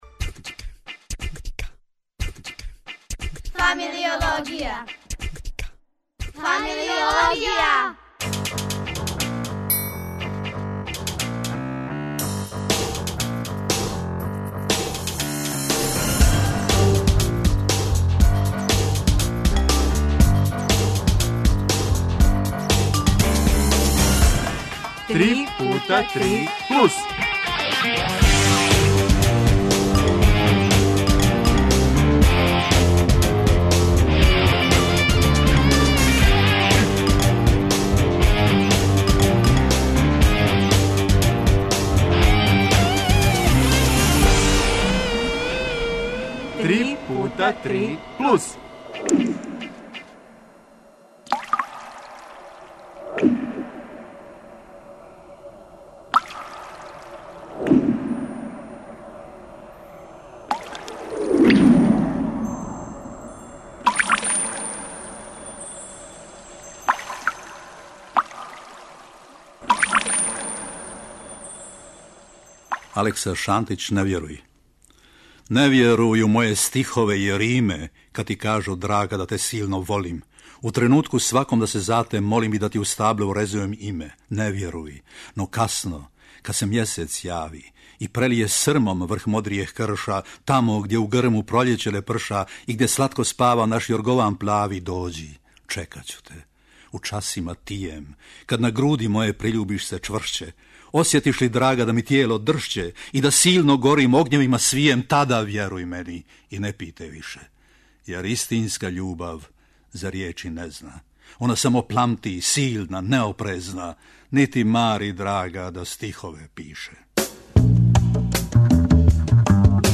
О томе данас уз вести и укључења.